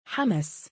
Hamas pronunciation ar 2
＊표준적인 악센트 위치는 「하」 부분